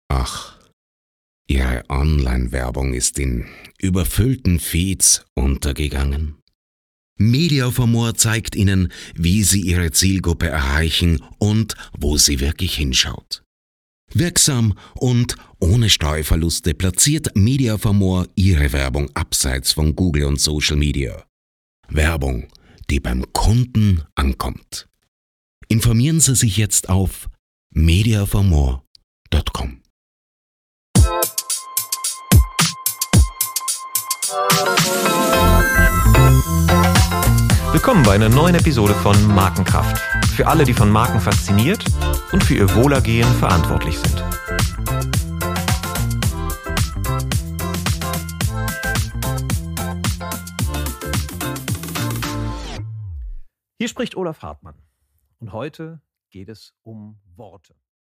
Beispiel Preroll
Podcast_DynamicAds_PREROLL_Beispielplatzierung_2025.mp3